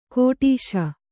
♪ kōṭīśa